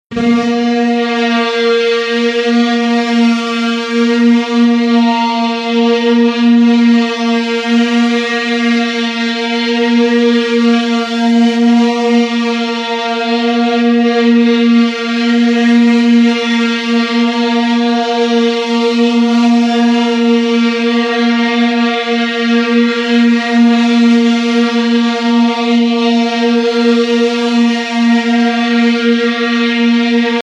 Звуки охранной сигнализации
Звук тревоги в учебном заведении